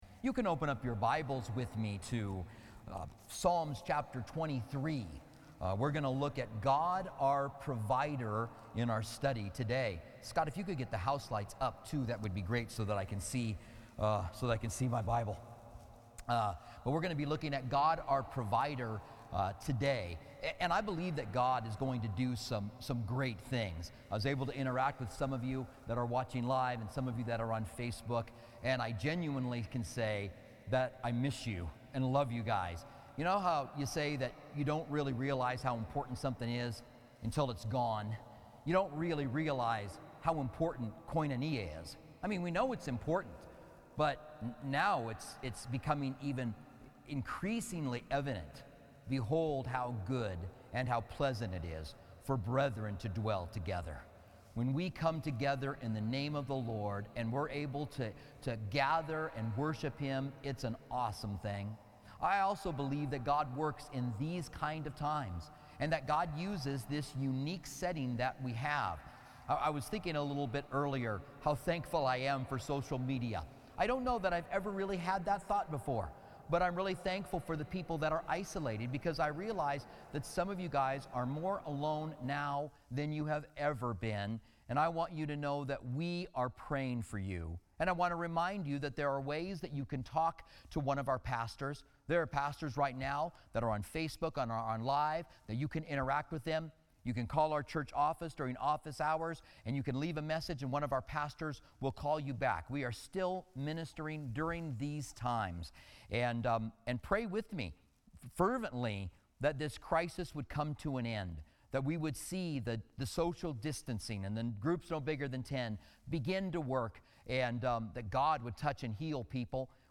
Special Messages